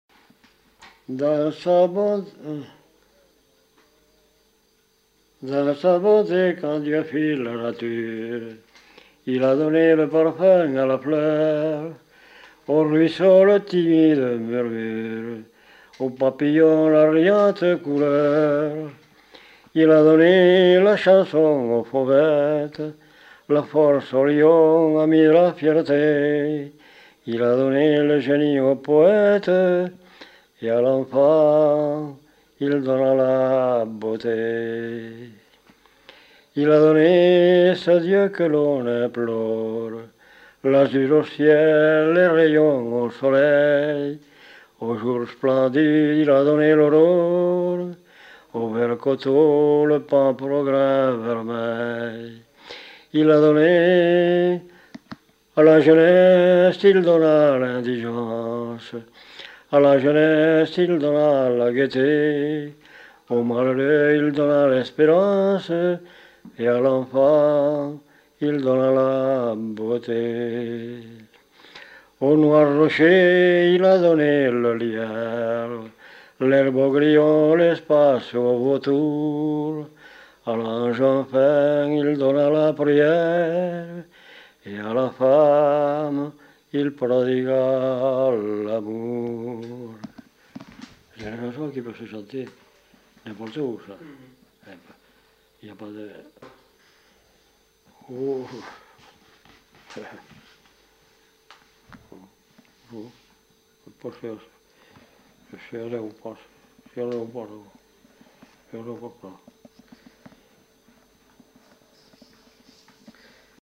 Aire culturelle : Lomagne
Lieu : Faudoas
Genre : chant
Effectif : 1
Type de voix : voix d'homme
Production du son : chanté